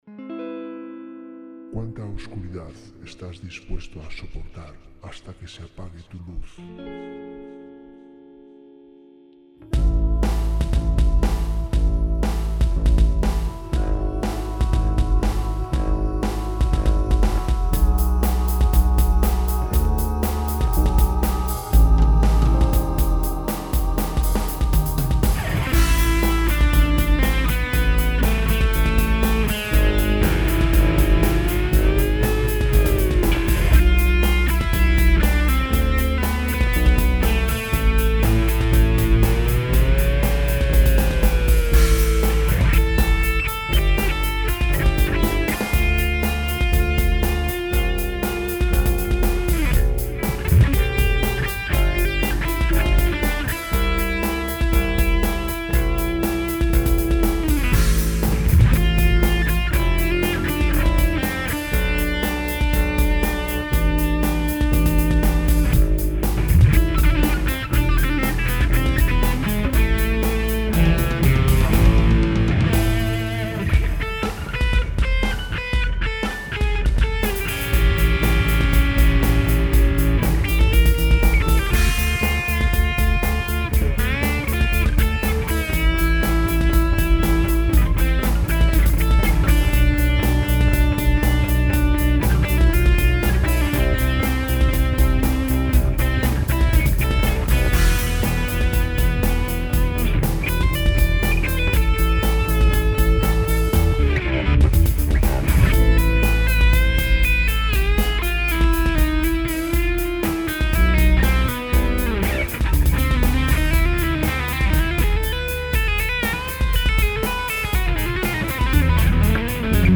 Audios Experimental